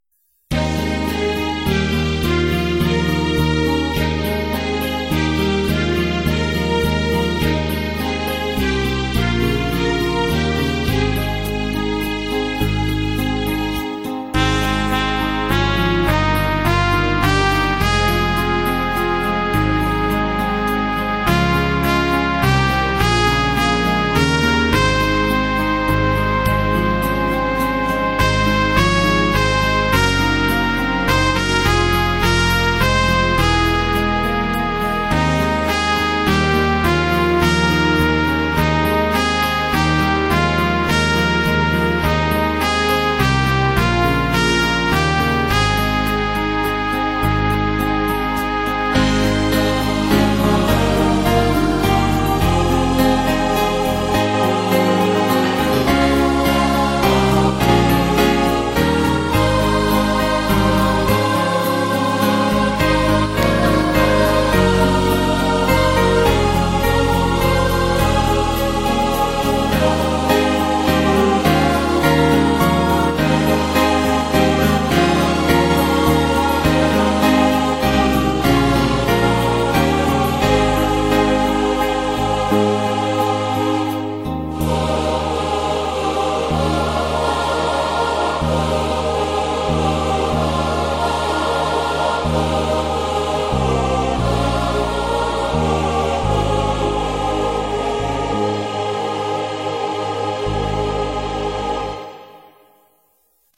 Bethel 04/26/2020 Service